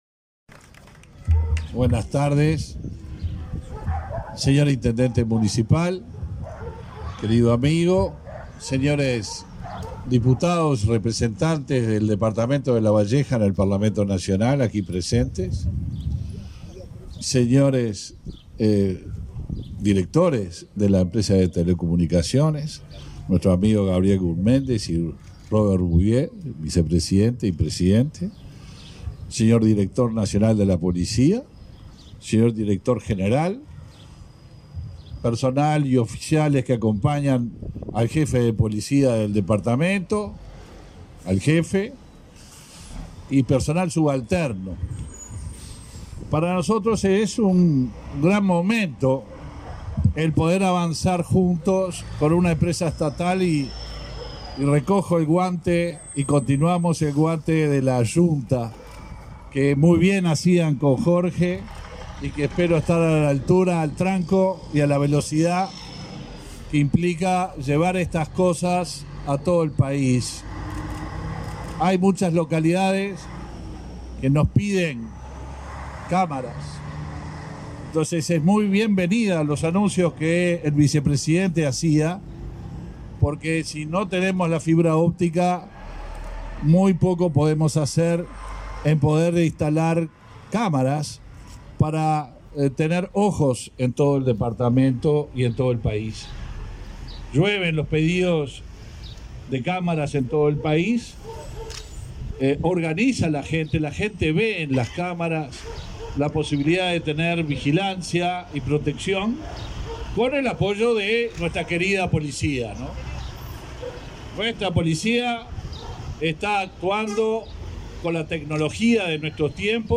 Conferencia de prensa por inauguración de cámaras de videovigilancia en Solís de Mataojo
Participaron en el acto el ministro Luis Alberto Heber; el intendente de Lavalleja, Mario García González; la alcaldesa Verónica Machado; el presidente de Antel, Gabriel Gurméndez, y el vicepresidente del ente Robert Bouvier.